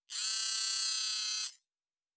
Servo Small 9_3.wav